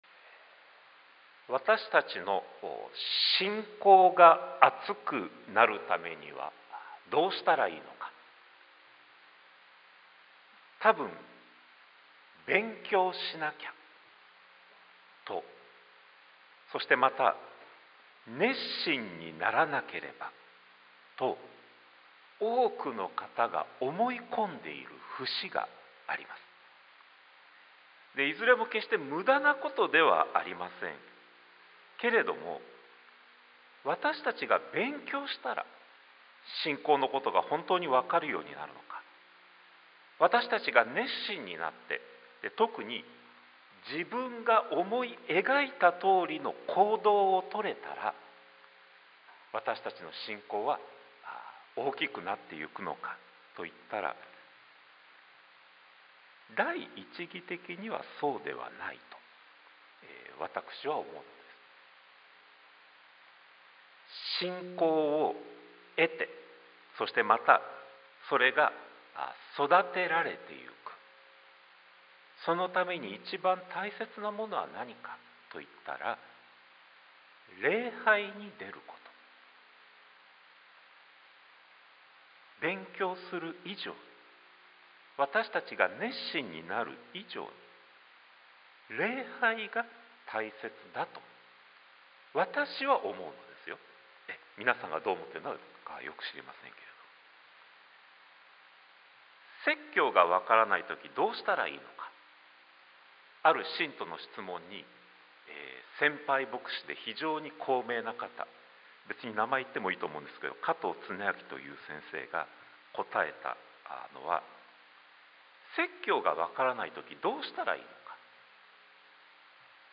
sermon-2023-10-22